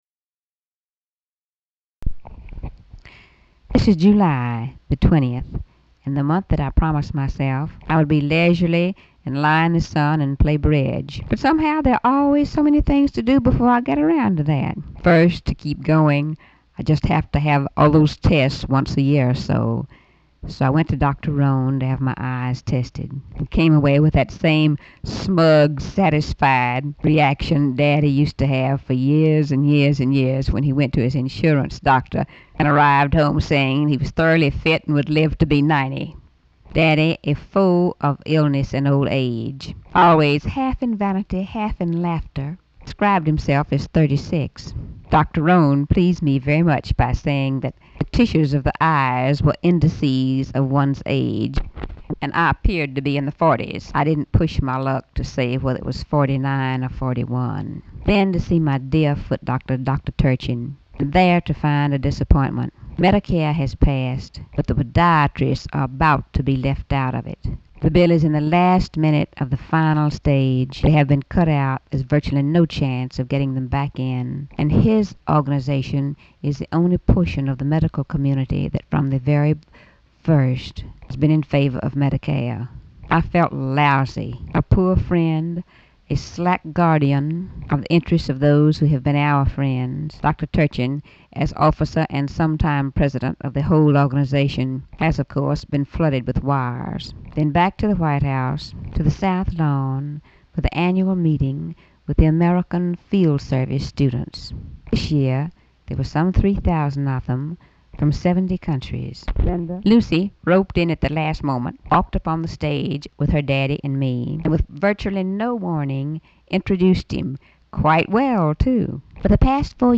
Audio diary and annotated transcript, Lady Bird Johnson, 7/20/1965 (Tuesday) | Discover LBJ